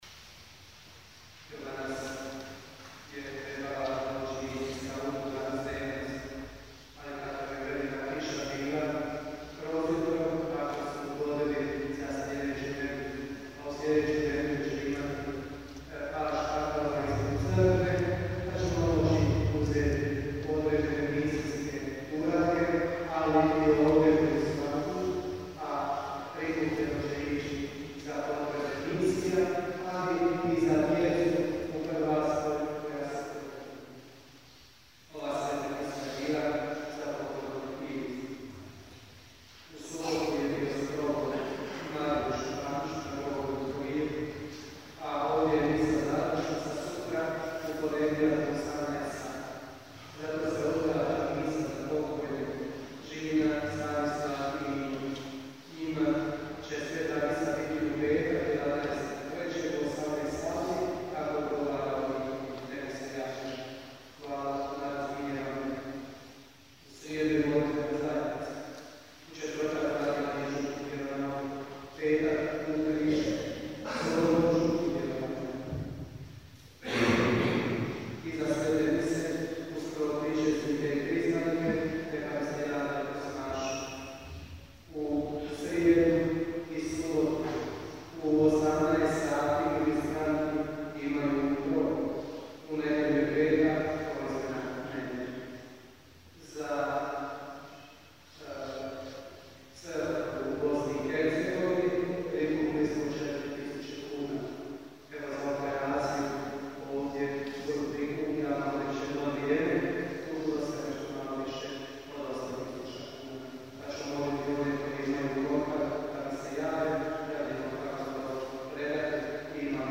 OBAVIJESTI I BLAGOSLOV